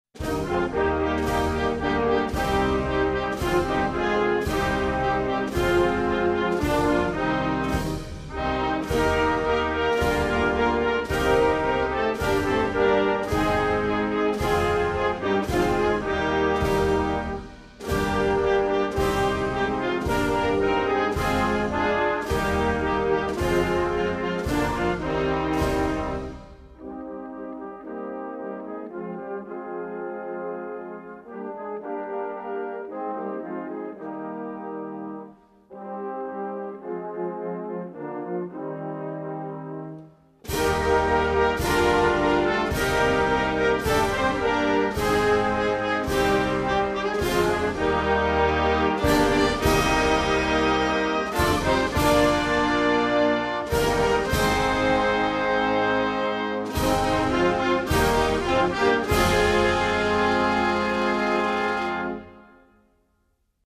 Jana_Gana_Mana_instrumental.wav